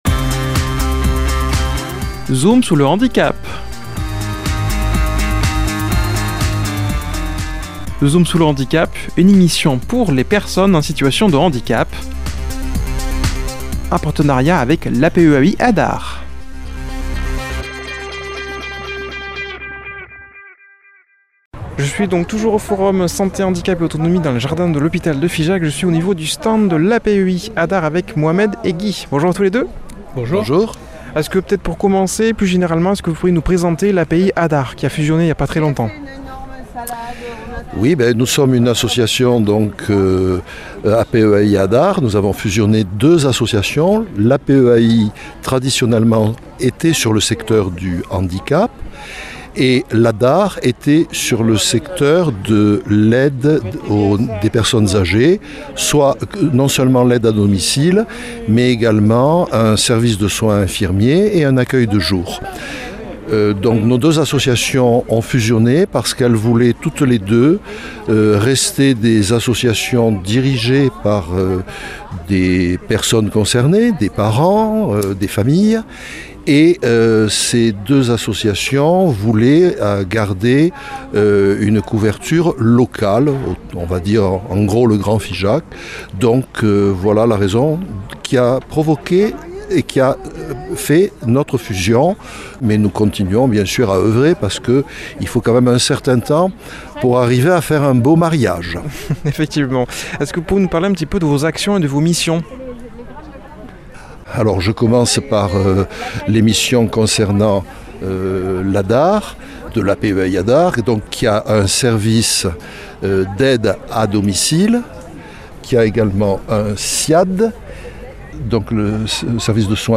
Un reportage en plusieurs parties tout au long de cette saison radiophonique. Aujourd’hui, avant dernier épisode, le numéro 8 avec le stand de l’APEAI ADAR